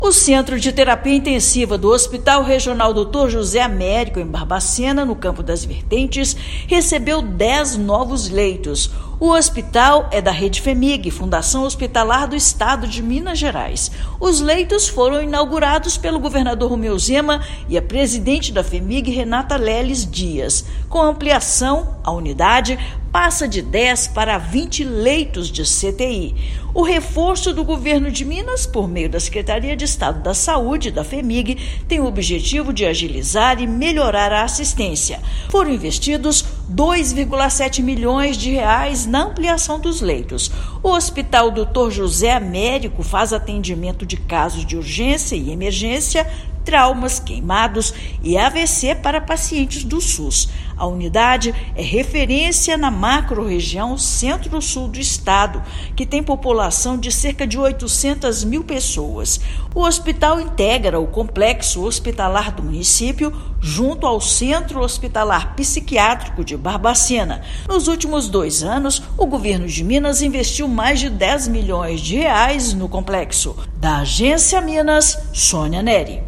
Ampliação vai possibilitar a realização de mais cirurgias, com atendimento ágil e redução da fila de espera em 51 municípios da região. Ouça matéria de rádio.